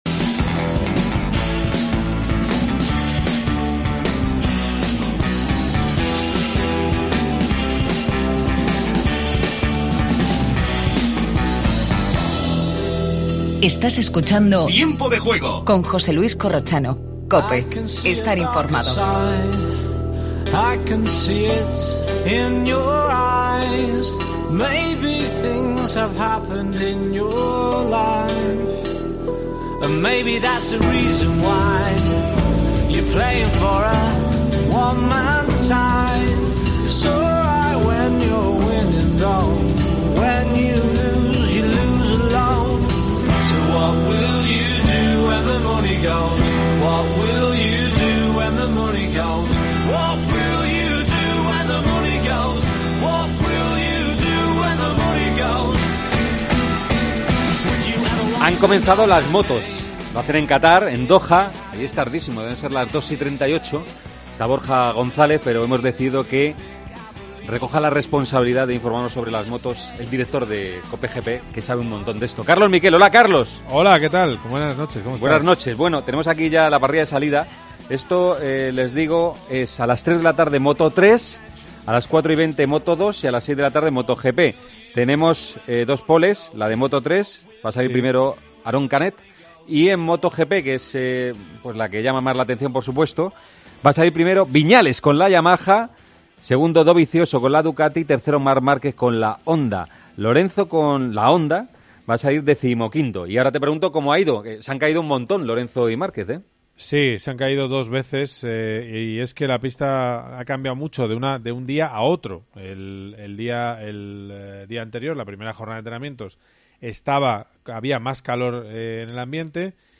Entrevista a Joana Pastrana.